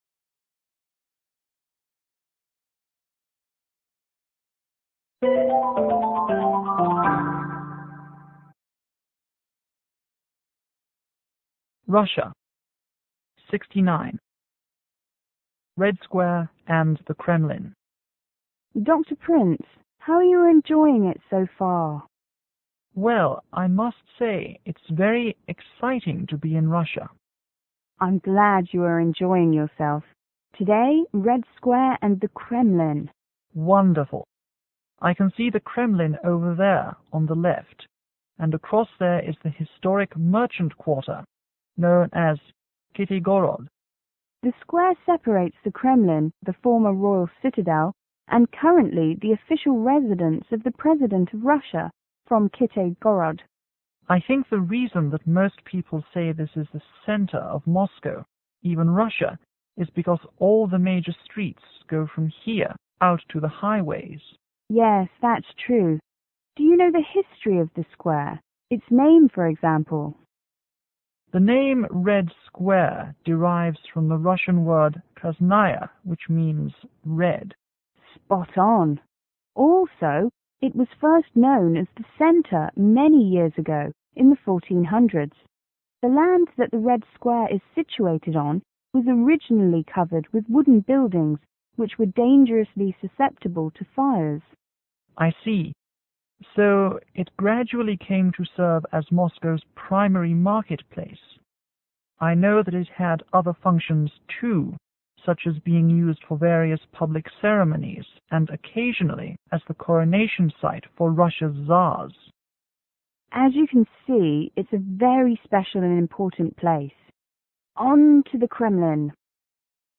P: Professor     VP: Visiting professor